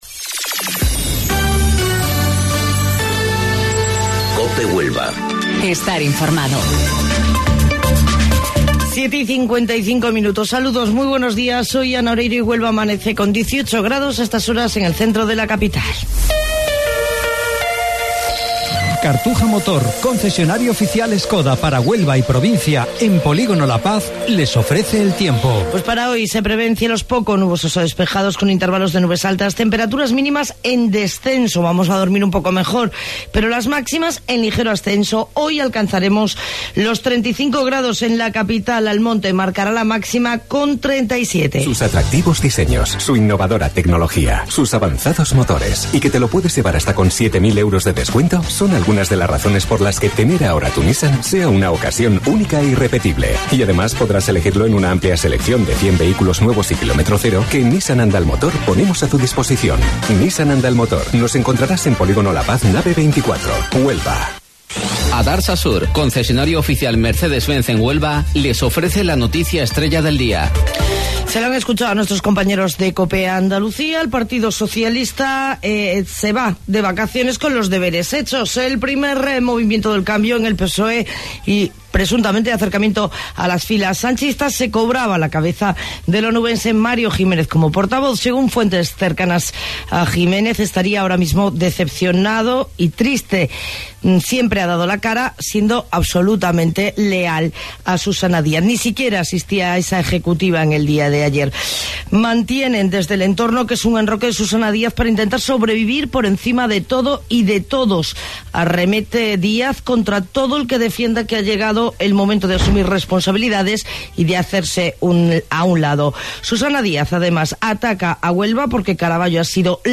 AUDIO: Informativo Local 07:55 del 30 de Julio